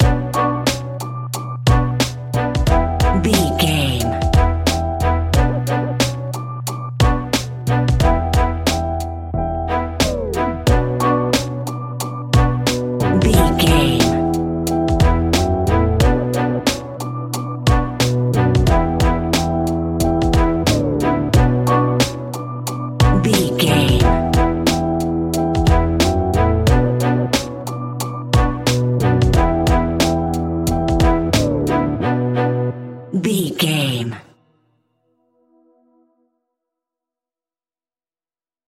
Aeolian/Minor
chilled
laid back
groove
hip hop drums
hip hop synths
piano
hip hop pads